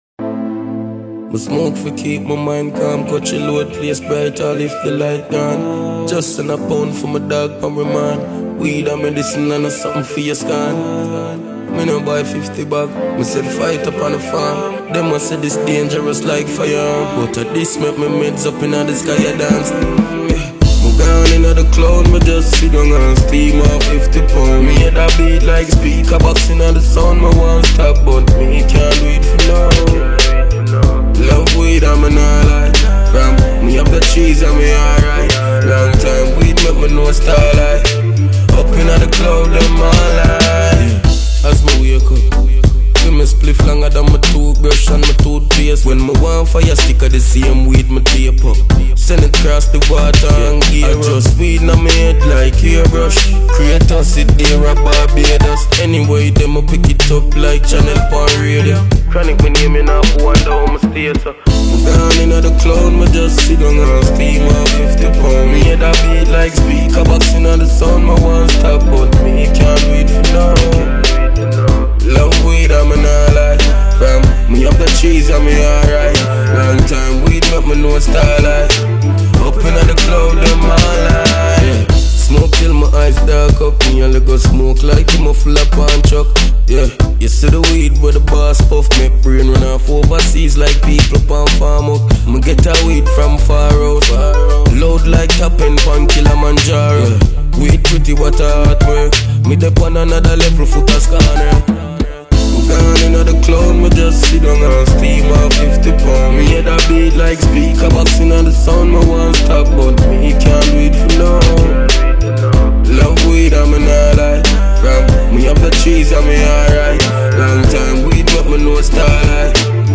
Jamaican award winning dancehall act